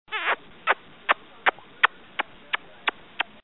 SFX啵啵亲嘴的声音音效下载